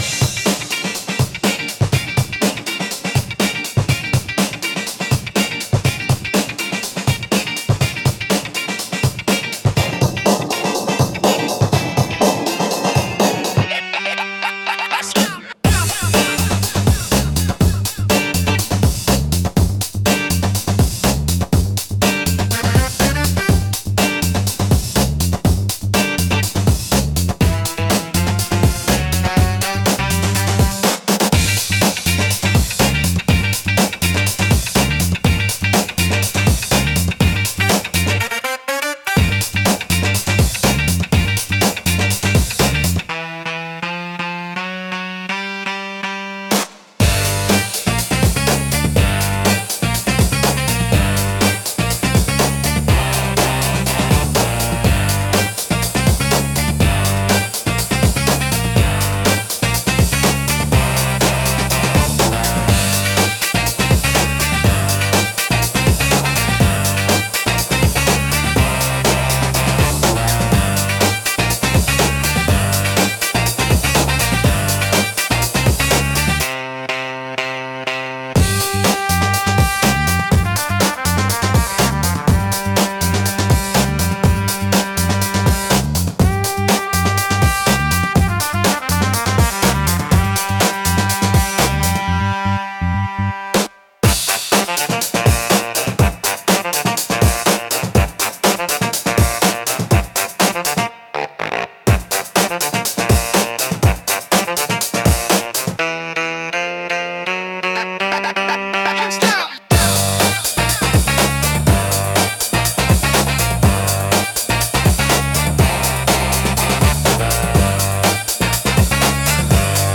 躍動感を生み出し、観客やプレイヤーのテンションを上げる効果が高く、飽きさせないペースで強いインパクトを与えます。